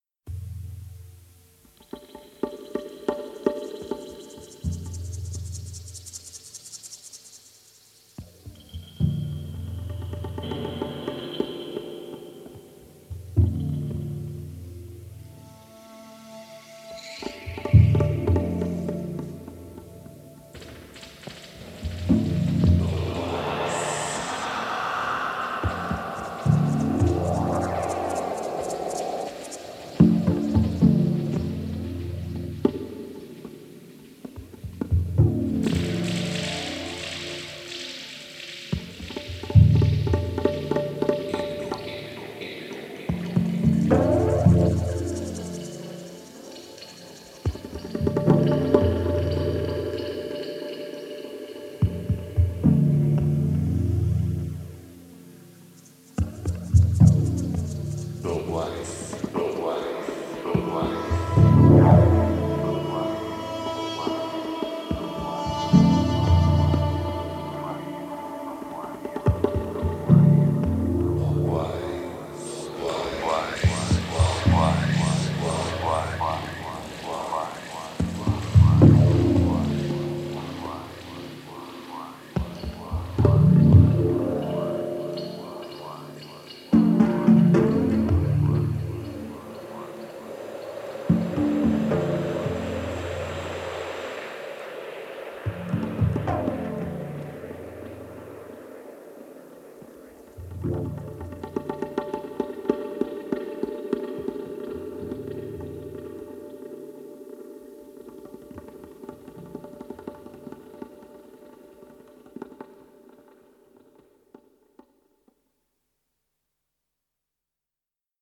無駄無く削ぎ落とされたモダンでミニマルな音響から浮かび上がるアーシーな郷愁。